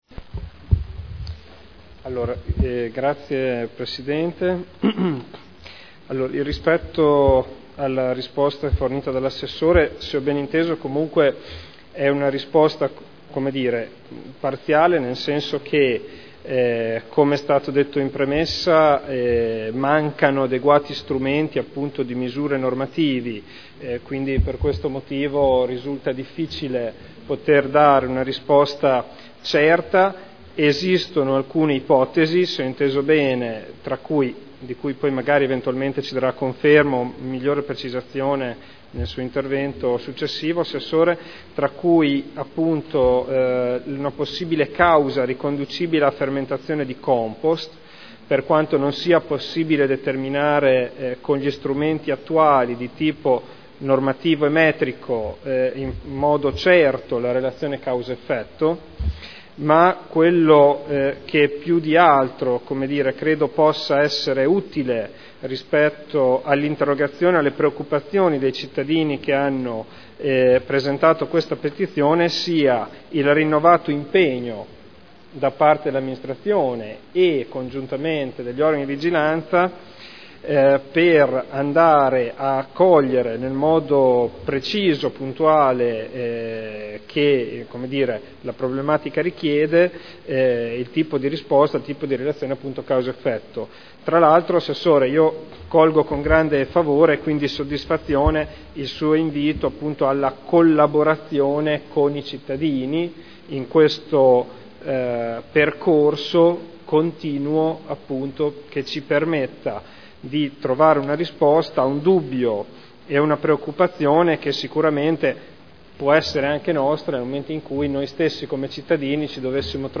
Seduta del 30/05/2011. Dibattito su interrogazione del consigliere Ricci (Sinistra per Modena) avente per oggetto: “Esalazione nell’aria”